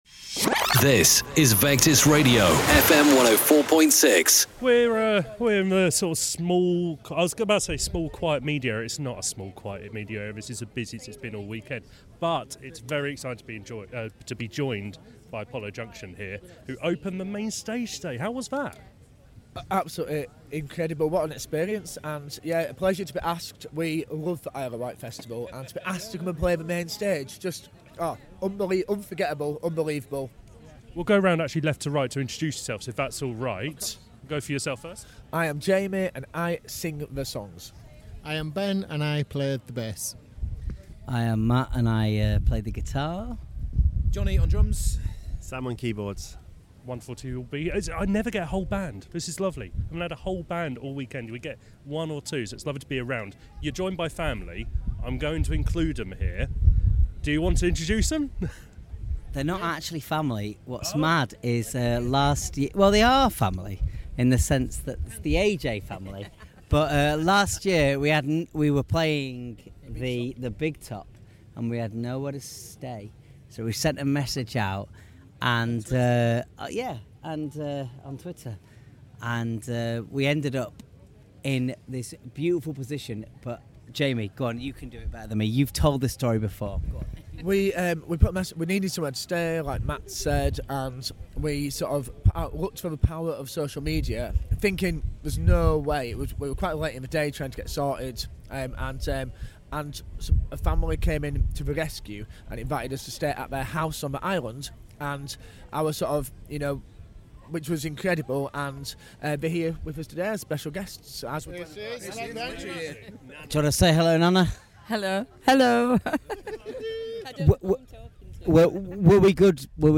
Leeds-based band Apollo Junction spoke to Vectis Radio after opening the Main Stage on Saturday of the Isle of Wight Festival. The topic of discussion? Taking their 2023 hosts as their special guests in 2024.